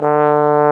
Index of /90_sSampleCDs/Roland L-CD702/VOL-2/BRS_Bs.Trombones/BRS_Bs.Bone Solo